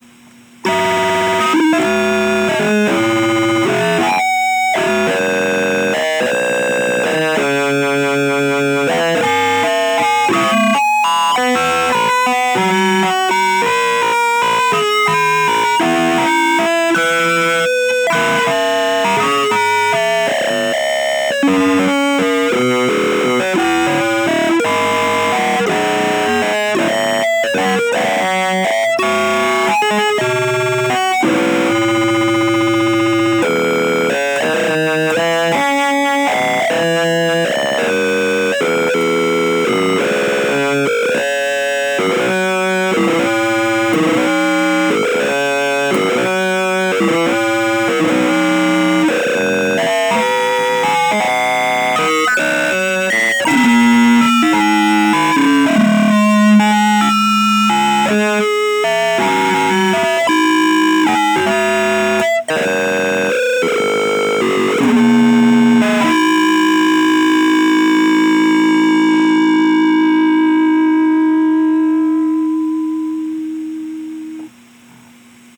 improvisations recorded january 1st - march 9th 2026
guitar, mandolin, drum, keyboard